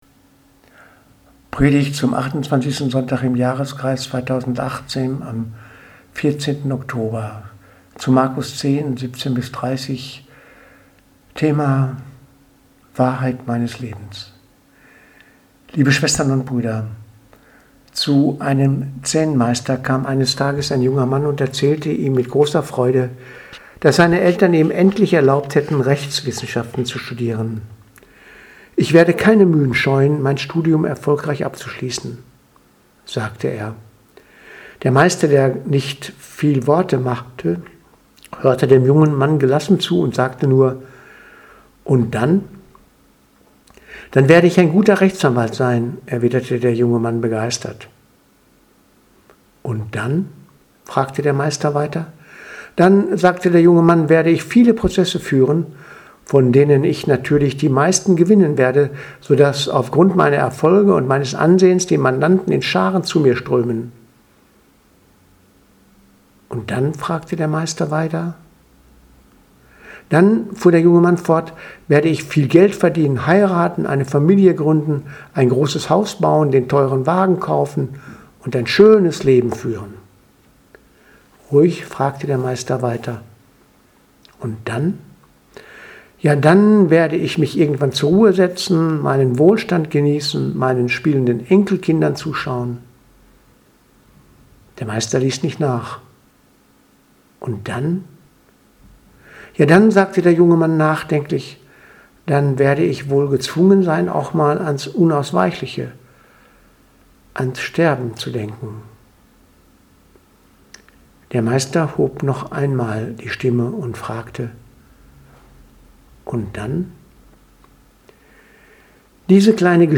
Predigt vom 14.10.2018 – Und dann